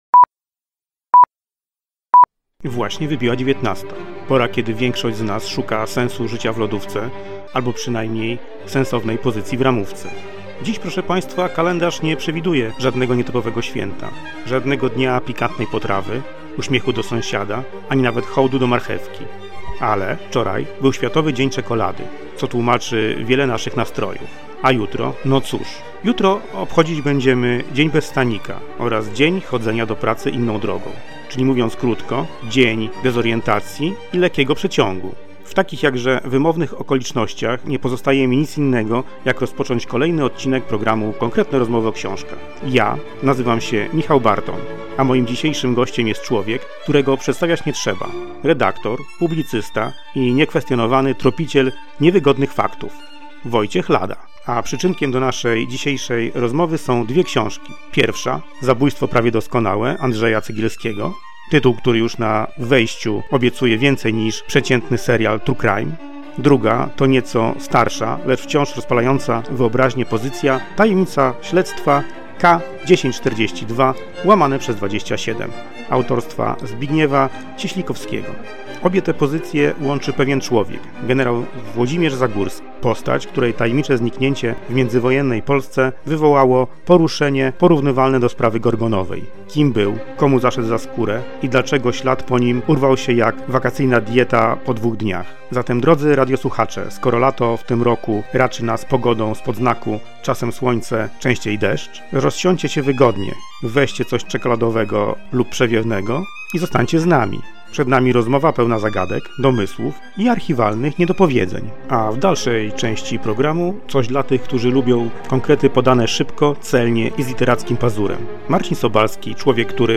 „Szybki Monolog o Książce”